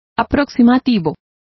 Complete with pronunciation of the translation of approximate.